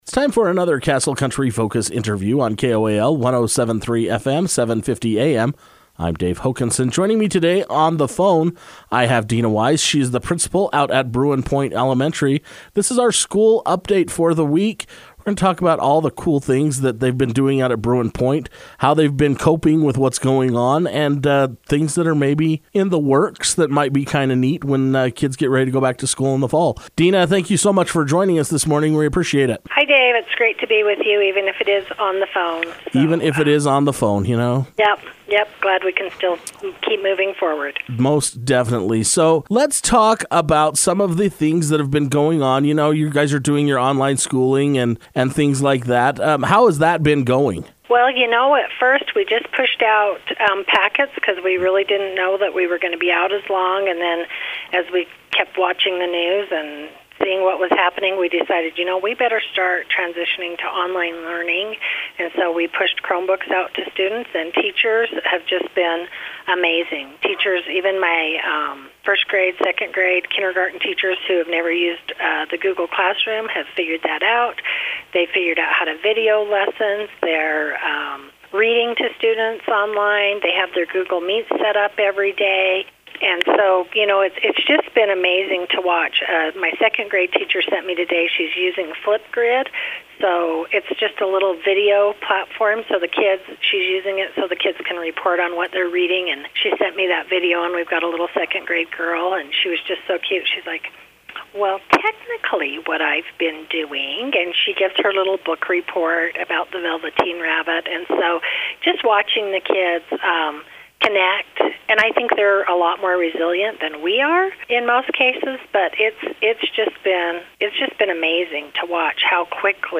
speak over the telephone with Castle Country Radio to share what the school has been up to.